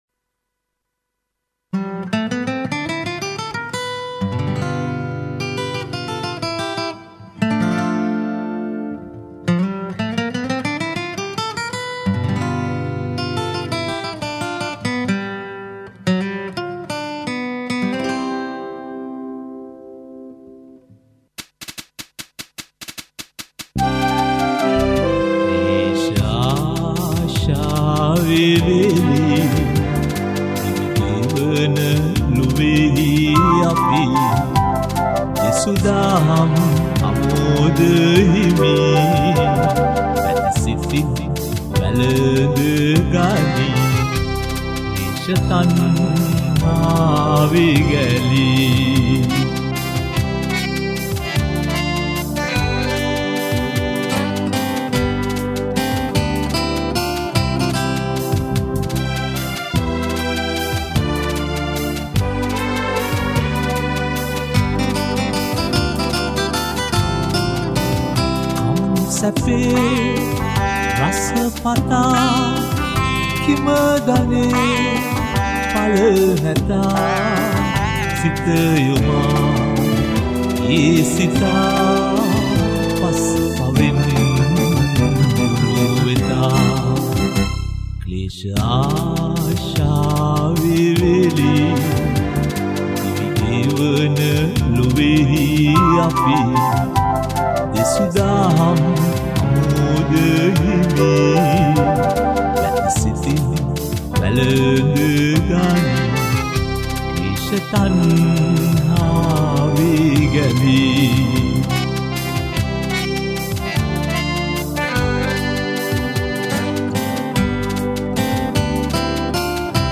All these songs were recorded (or remastered) in Australia.